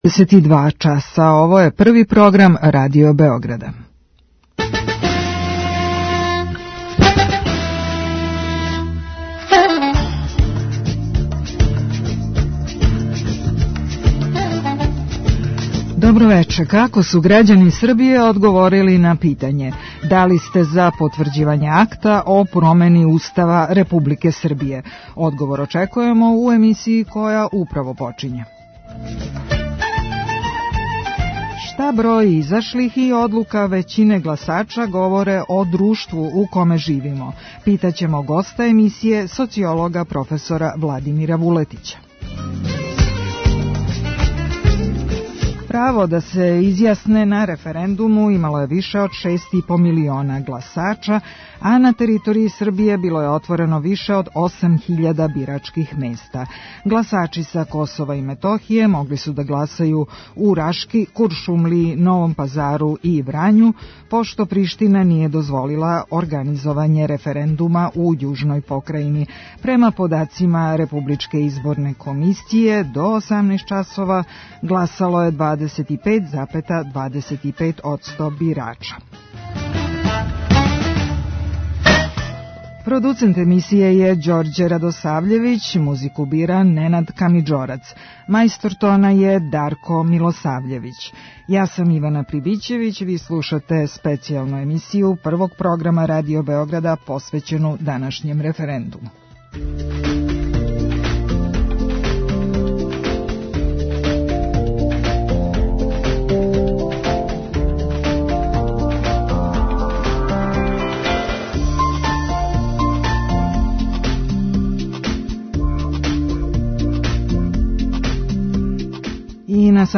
Reporteri Prvog programa Radio Beograda javljaju se iz Republičke izborne komisije i iz različitih delova Srbije